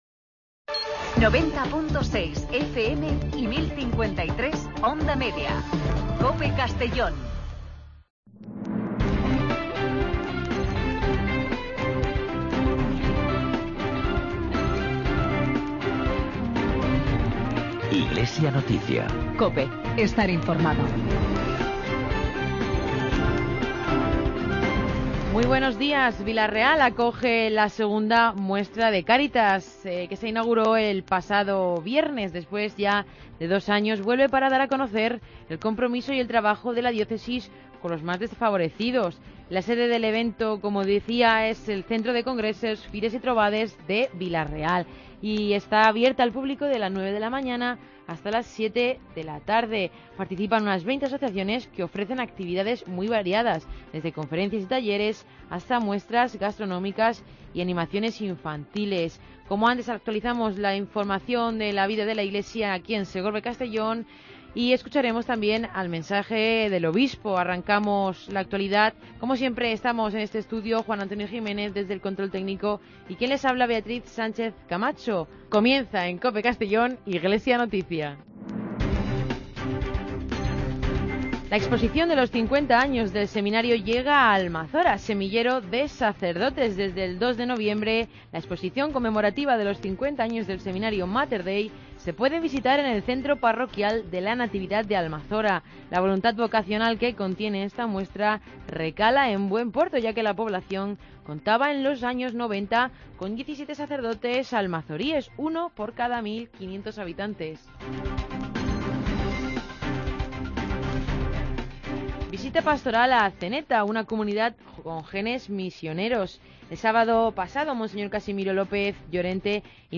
Espacio informativo de la actualidad diocesana de Segorbe-Castellón